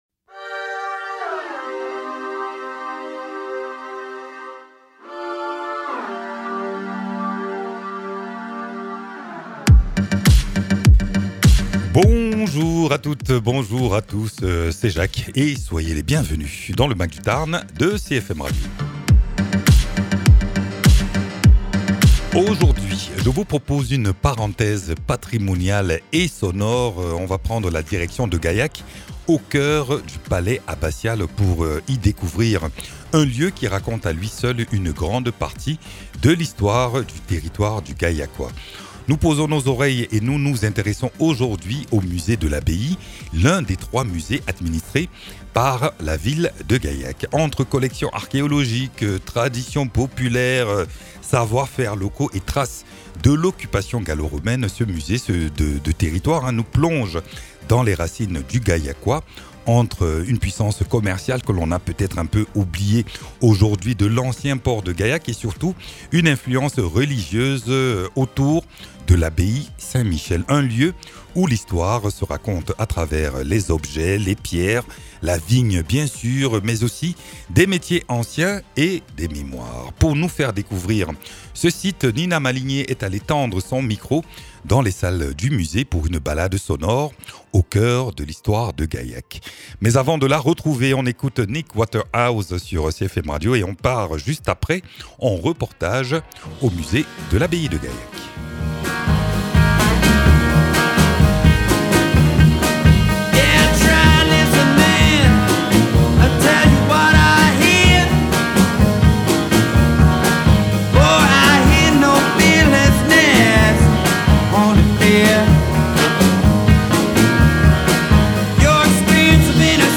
Entre mosaïque gallo-romaine, patrimoine religieux et savoir-faire locaux, le musée de l’Abbaye de Gaillac se découvre en immersion sonore dans ce reportage du Mag du Tarn, une invitation à explorer l’histoire et les richesses culturelles du Gaillacois.